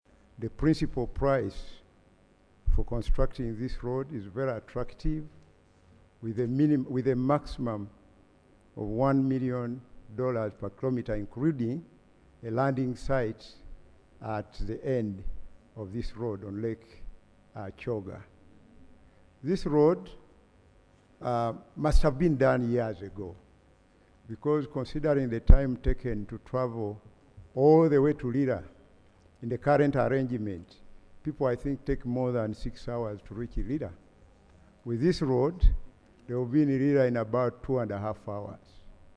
Minister of State for Finance (Planning), Hon. Amos Lugoloobi, speaking during the House sitting on Tuesday 16 December 2025
AUDIO: Minister Lugoloobi